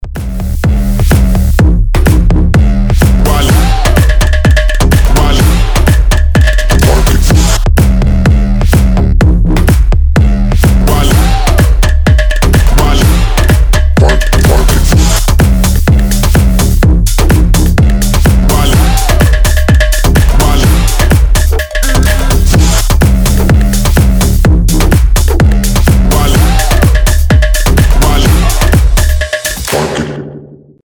громкие
жесткие
мощные
EDM
Bass House